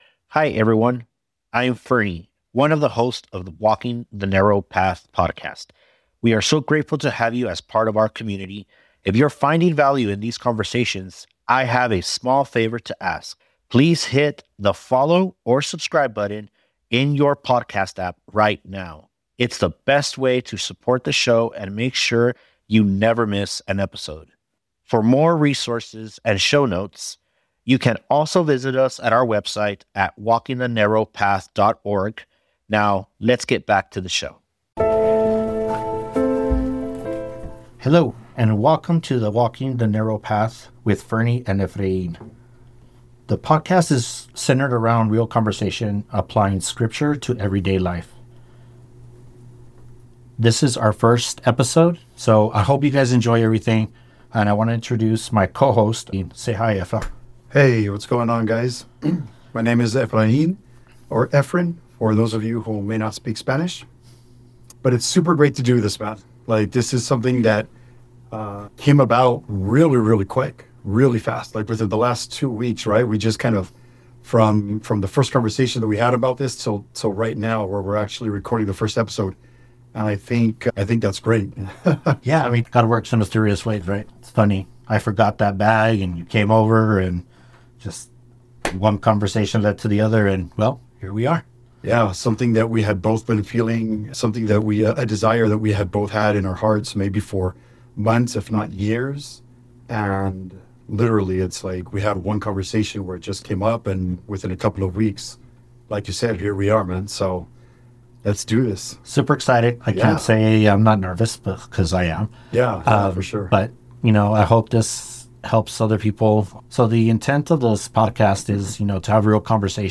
Recording from El Paso, Texas, they explain the show’s title and foundational scripture, Matthew 7:14, discussing the narrow gate and difficult way that leads to life, and how modern culture often rejects absolute truth.